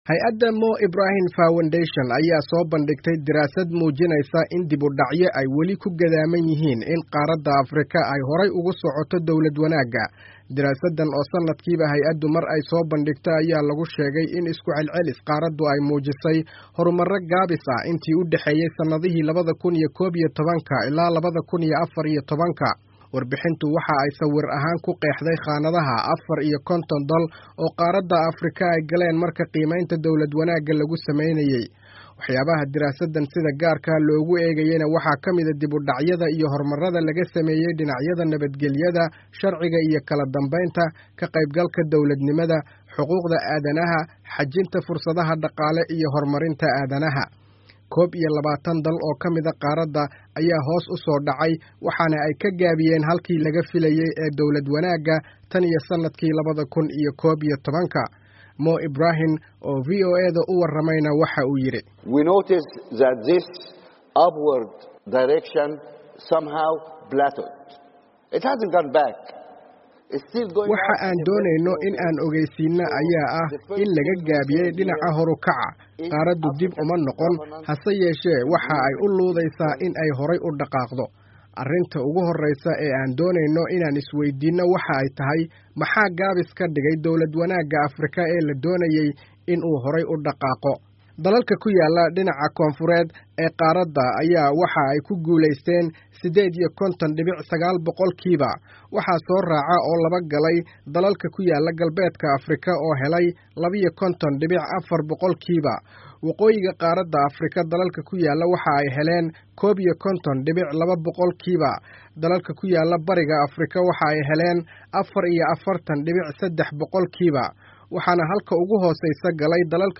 LONDON —